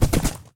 horse
gallop2.ogg